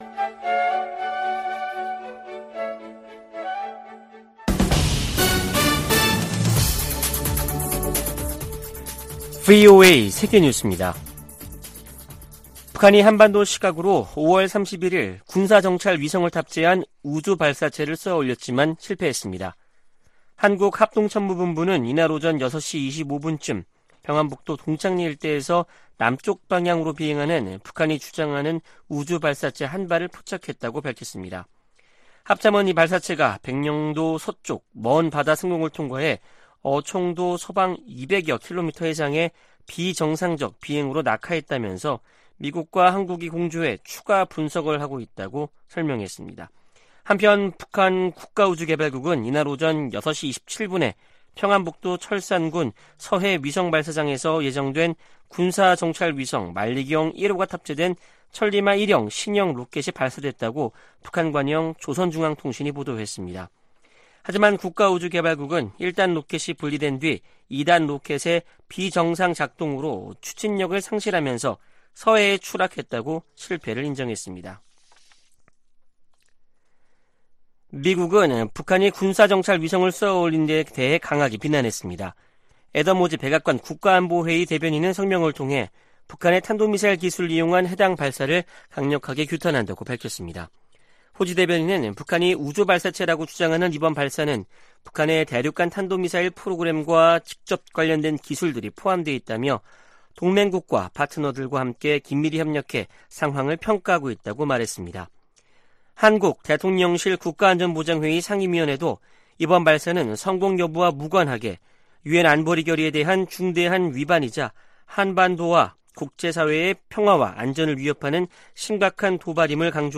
VOA 한국어 아침 뉴스 프로그램 '워싱턴 뉴스 광장' 2023년 6월 1일 방송입니다. 북한은 31일 정찰위성 발사가 엔진고장으로 실패했다고 발표했습니다.